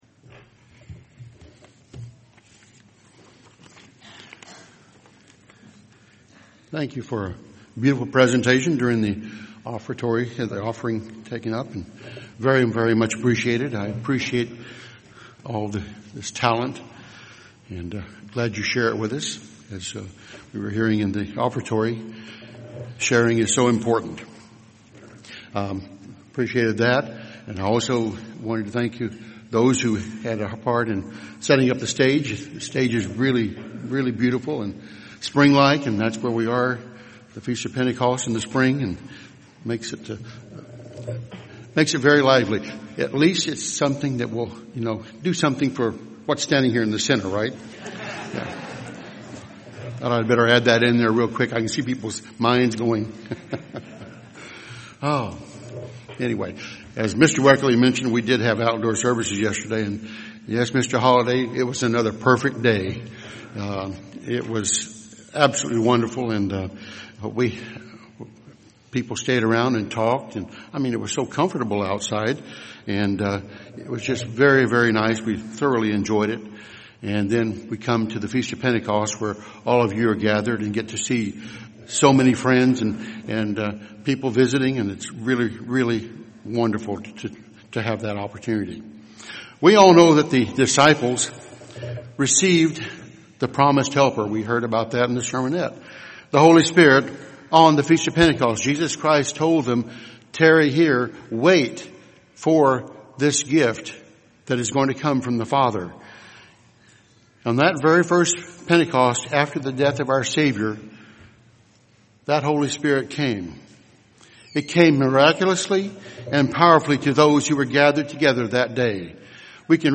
Sermons
Given in Cincinnati North, OH